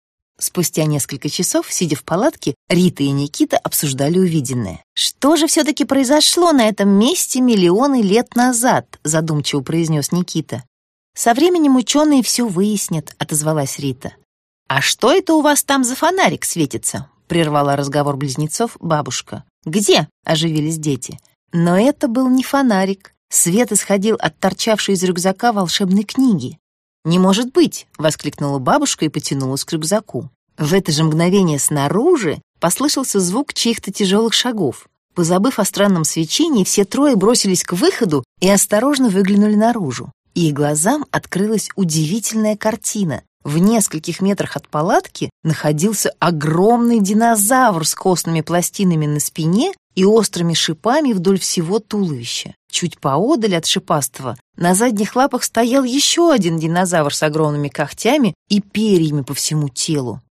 Аудиокнига По следам динозавров. Путешествие в меловой период | Библиотека аудиокниг